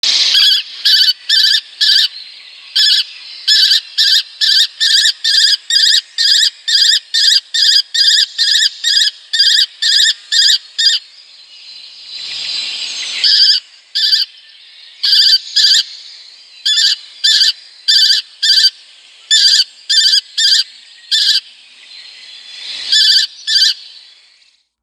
Vânturelul roșu (Falco tinnunculus)
Ascultă strigătul ascuțit al vânturelului!